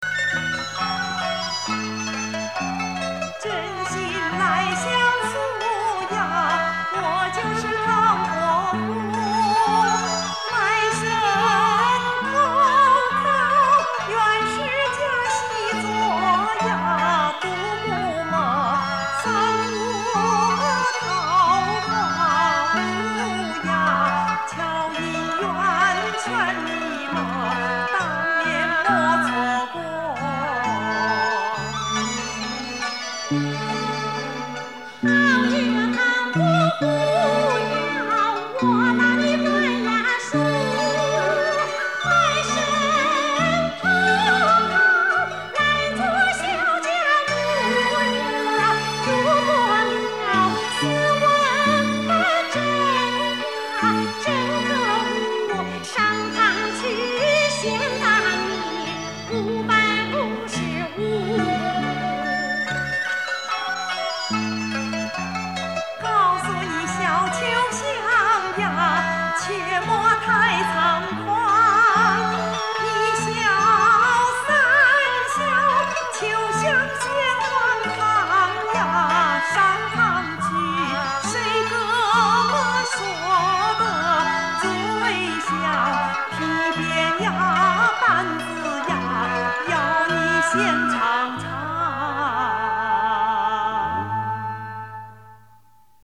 音乐主要采用大家熟悉的江南小调，男声部运用 戏曲中小生的唱法来演唱